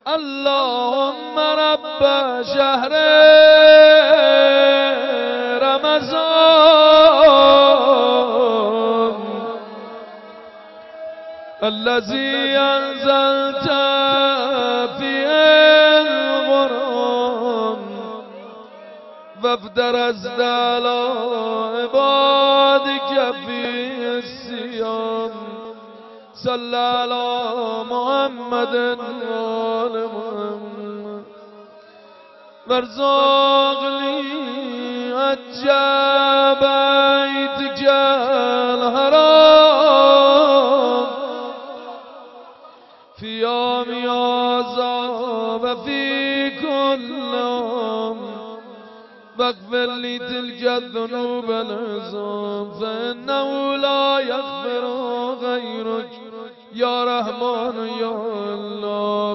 وارث: آوای انتظار ویژه ماه مبارک رمضان برای مشترکین همراه اول